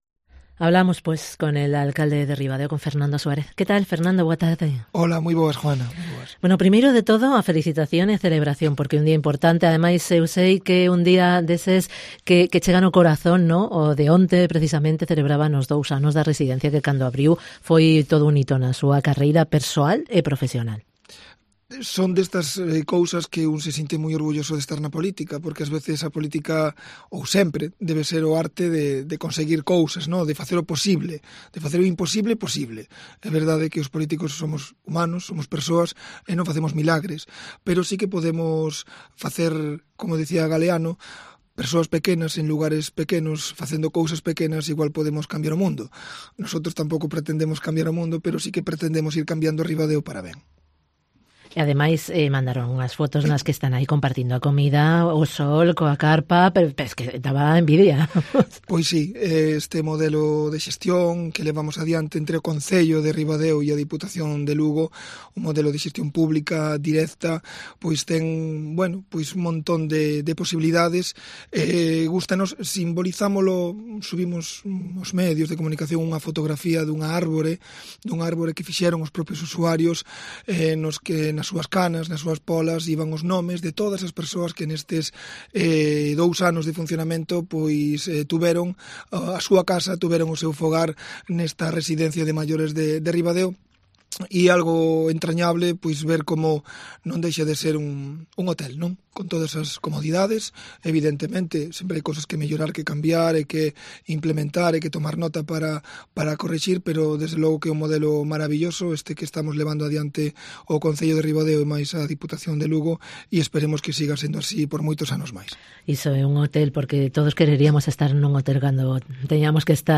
Podcast: Charlamos con FERNANDO SUÁREZ, alcalde de Ribadeo